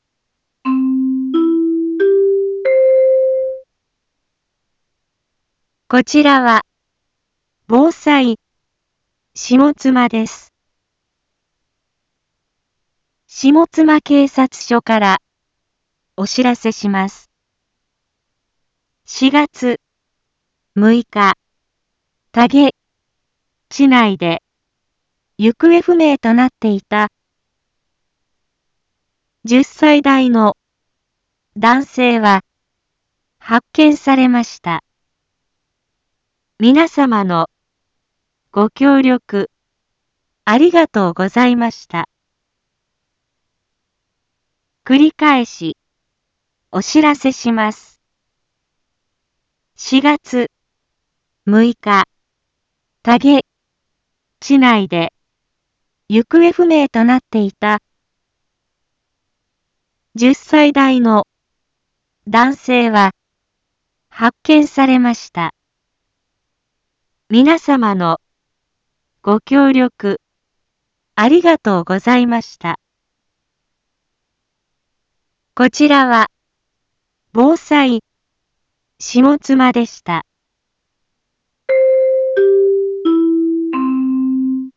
一般放送情報
Back Home 一般放送情報 音声放送 再生 一般放送情報 登録日時：2022-04-07 12:16:34 タイトル：行方不明者の発見について インフォメーション：こちらは、防災下妻です。